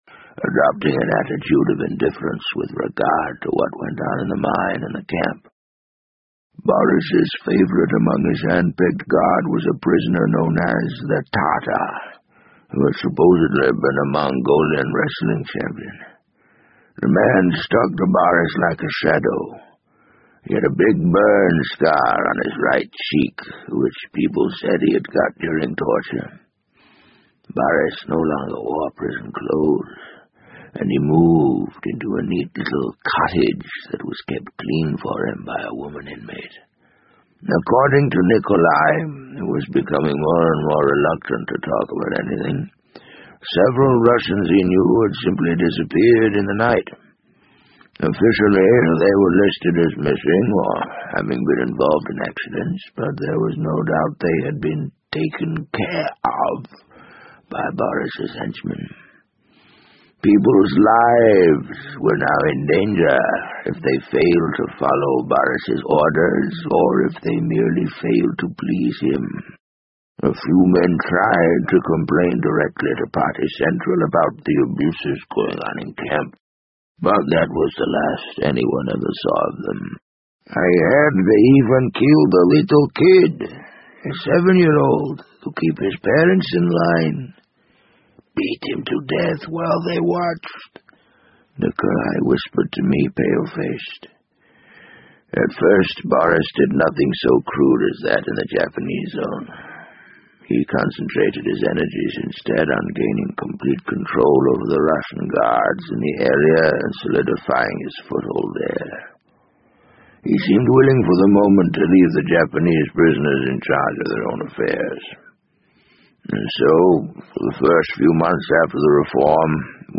BBC英文广播剧在线听 The Wind Up Bird 014 - 13 听力文件下载—在线英语听力室